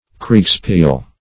Search Result for " kriegsspiel" : The Collaborative International Dictionary of English v.0.48: Kriegsspiel \Kriegs"spiel`\, n. [G., fr. krieg war + spiel play.] A game of war, played for practice, on maps.